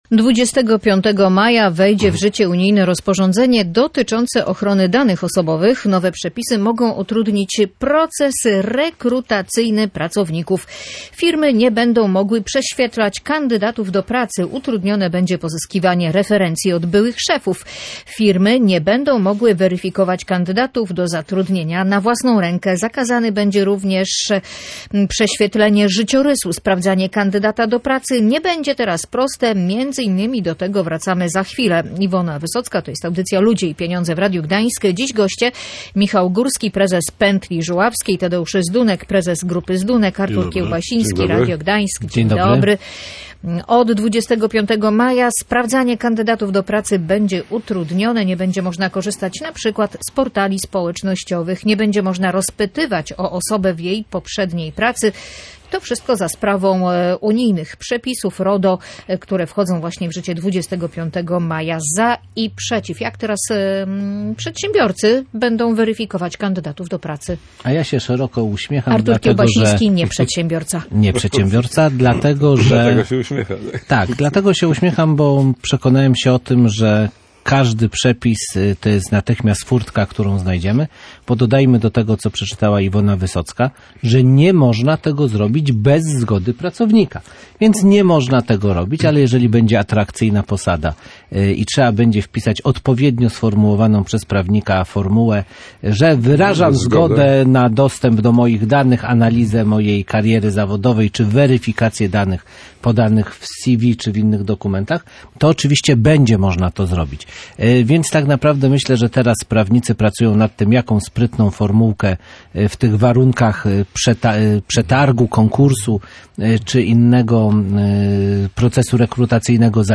Między innymi o tym rozmawiali goście audycji Ludzie i Pieniądze